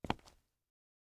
Illusion-UE5/Concrete Walk - 0002 - Audio - Stone 02.ogg at dafcf19ad4b296ecfc69cef996ed3dcee55cd68c